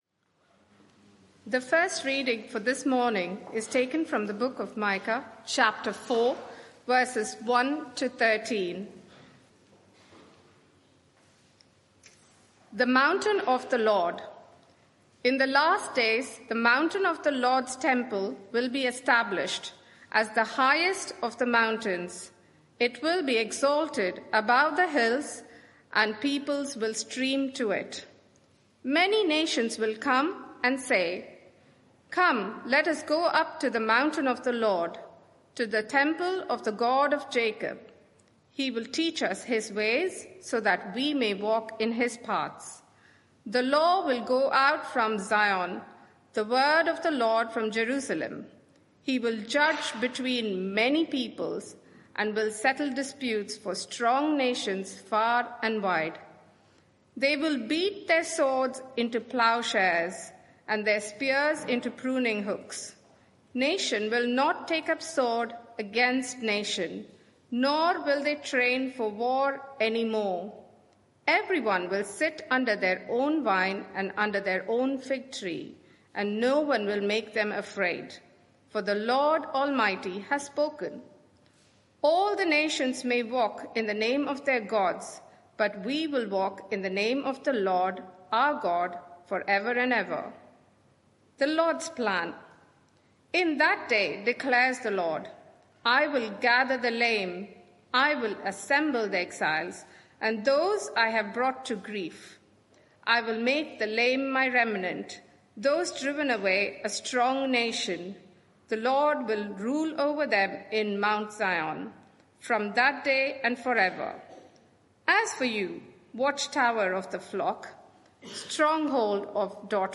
Media for 11am Service on Sun 15th Jun 2025 11:00 Speaker
Theme: Hope Restored There is private media available for this event, please log in. Sermon (audio) Search the media library There are recordings here going back several years.